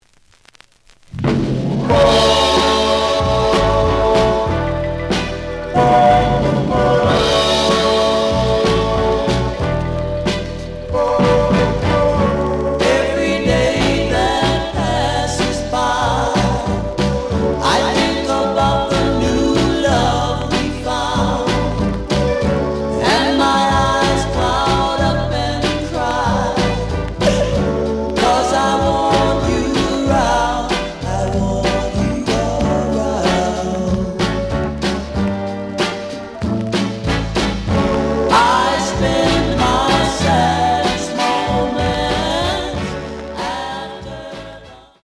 試聴は実際のレコードから録音しています。
●Format: 7 inch
●Genre: Soul, 60's Soul